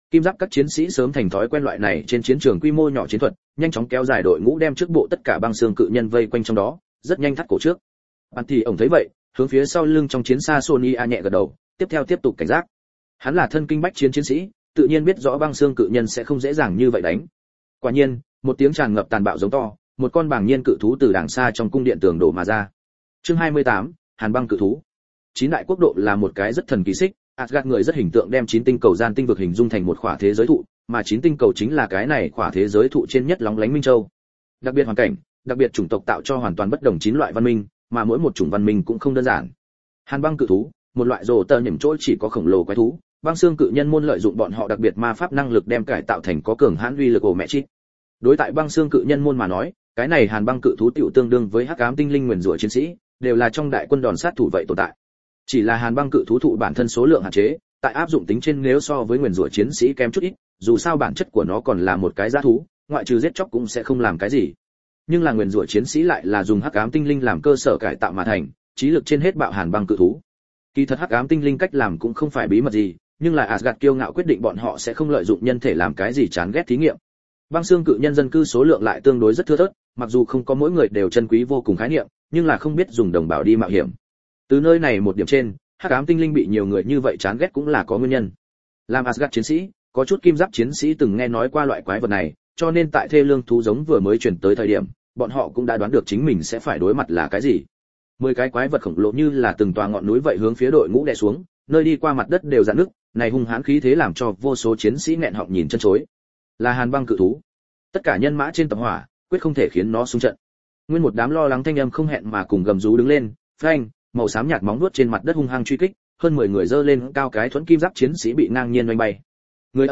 Ta Đến Từ Asgard Audio - Nghe đọc Truyện Audio Online Hay Trên TH AUDIO TRUYỆN FULL